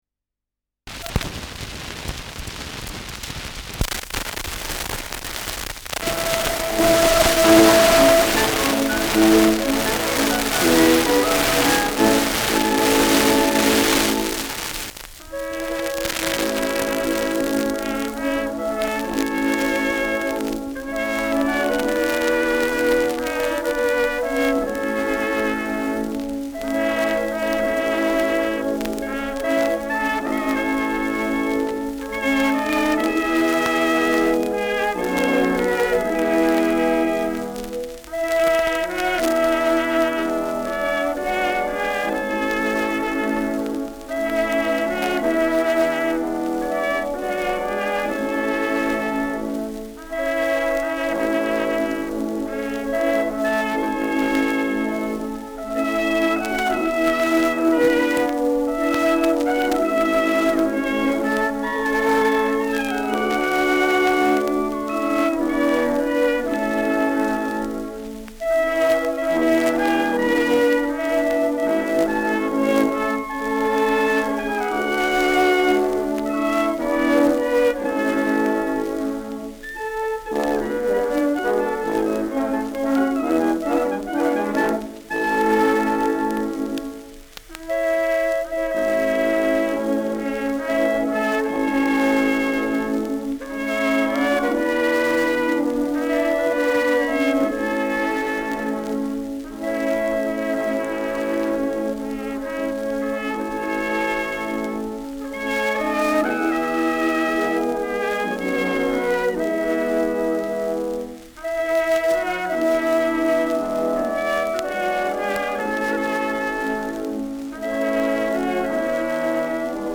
Schellackplatte
Anfang stark verrauscht : Durchgehend leichtes bis stärkeres Knacken : Verzerrt an lauteren Stellen : Leiern